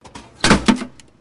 Vending Machine.wav